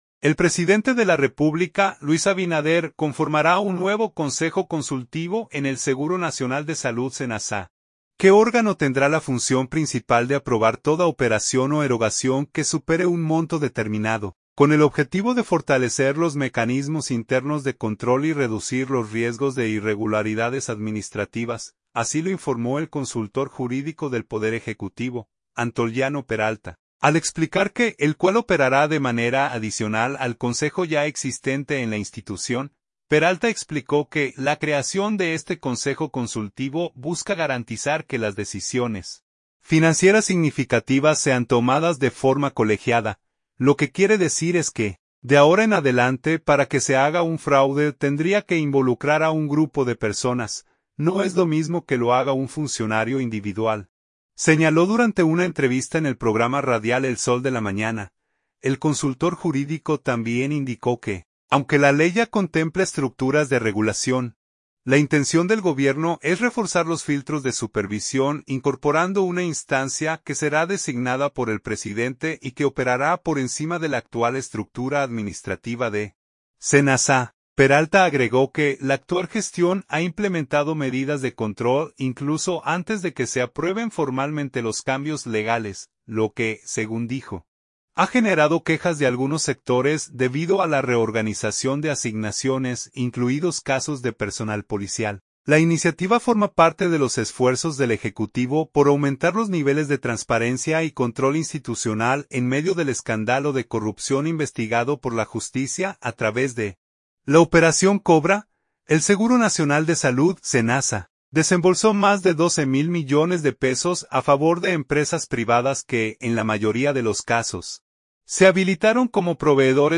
“Lo que quiere decir es que, de ahora en adelante, para que se haga un fraude tendría que involucrar a un grupo de personas. No es lo mismo que lo haga un funcionario individual”, señaló durante una entrevista en el programa radial El Sol de la Mañana.